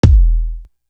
Air It Out Kick.wav